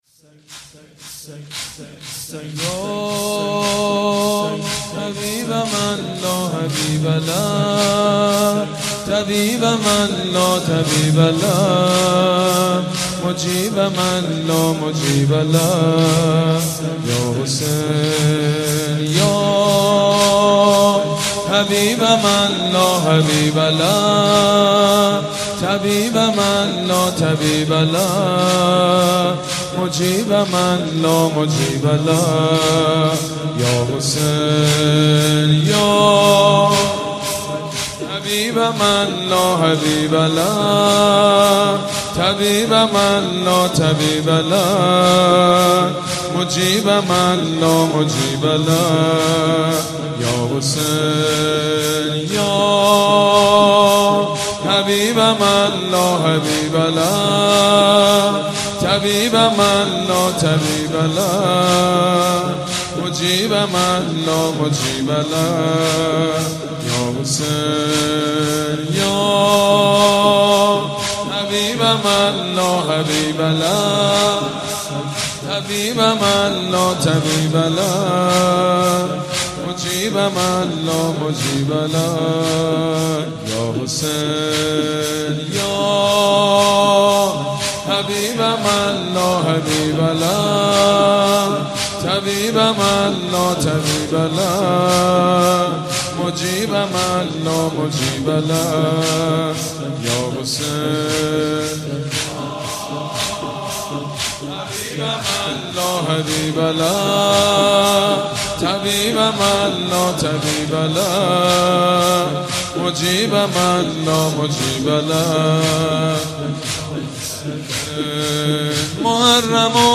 سینه زنی بنی فاطمه یا حبیب من لاحبیب له...